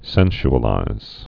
(sĕnsh-ə-līz)